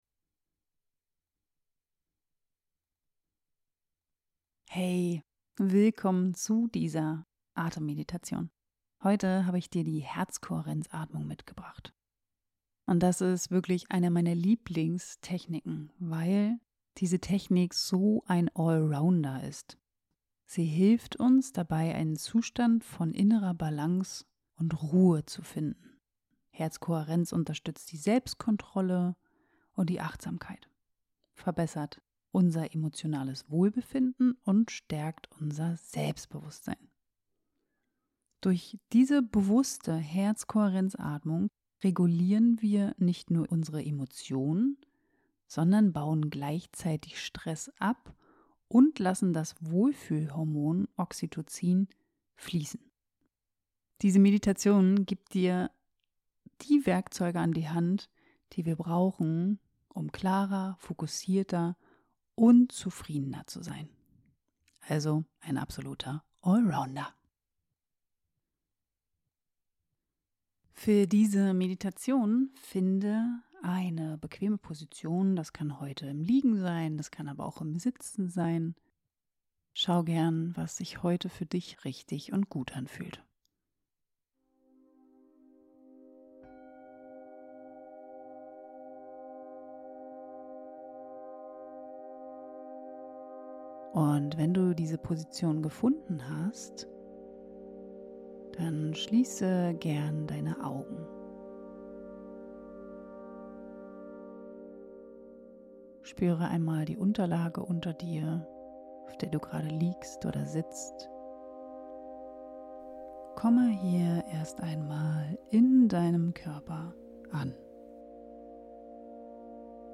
Atemmeditation - Finde zurück in deine Mitte (extra Länge) ~ Atemgesundheit - Balance statt Hektik Podcast
Mach’s dir bequem, schließ die Augen und lass dich von meinem Atem-Flow begleiten.